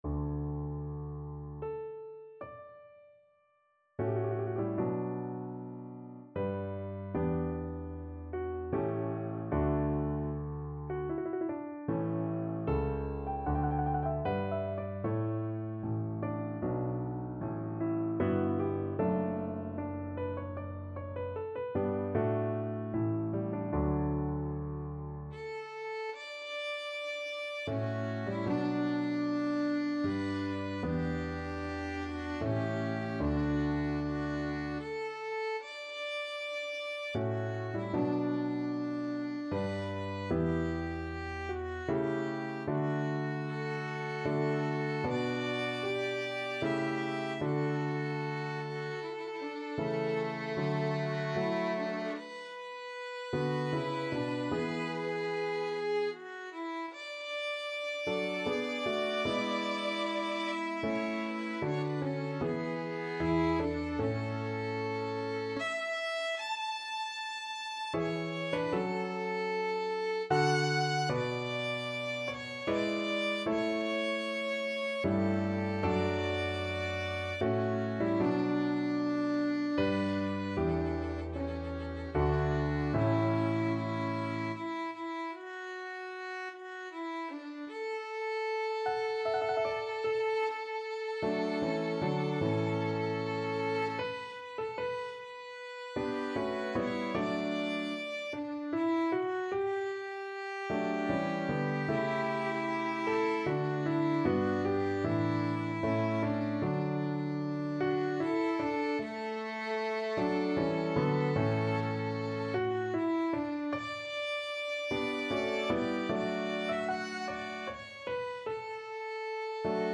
Violin 1Violin 2
Larghetto (=76)
3/4 (View more 3/4 Music)
Classical (View more Classical Violin Duet Music)